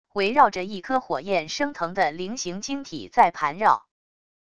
围绕着一颗火焰升腾的菱形晶体在盘绕wav音频